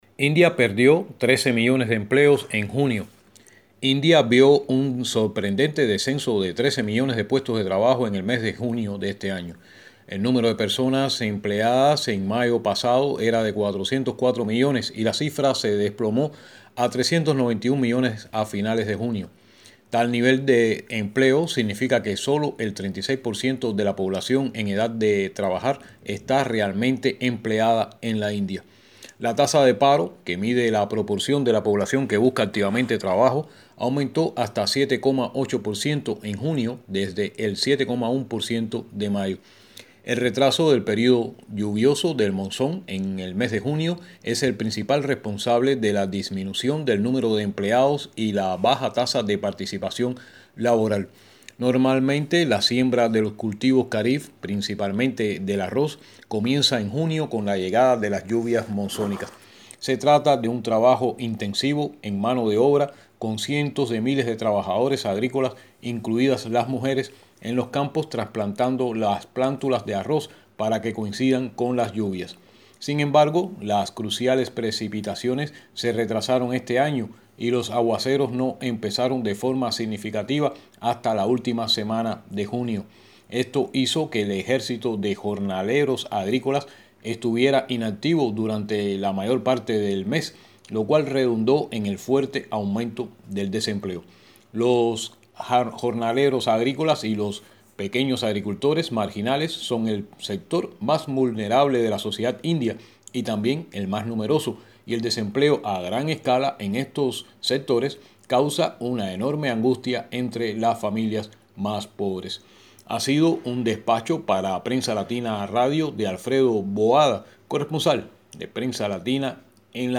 desde Nueva Delhi